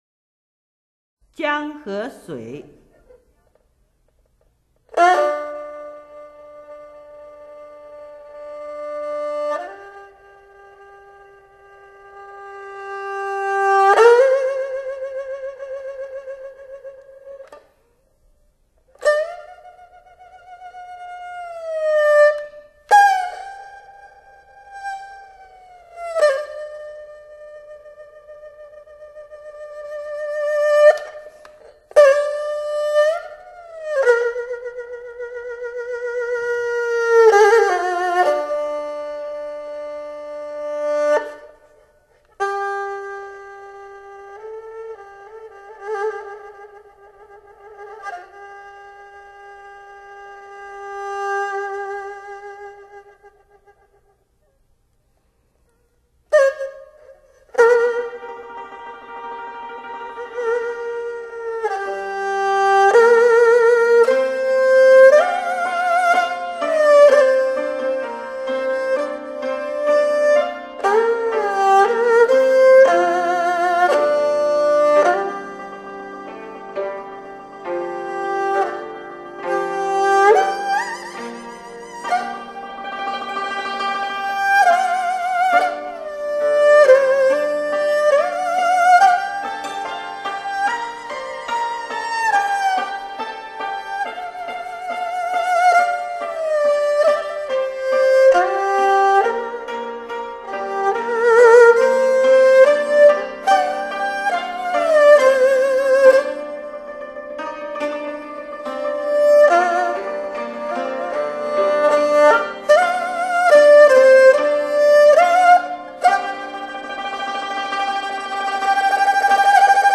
二胡
乐曲一共分三段，这三段中，前后两段是哭声，中间短短的乐曲是回忆。
后一段，由于从片刻幸福回忆中回到现实，这巨大反差下，其哭声则是凄凉、绝望。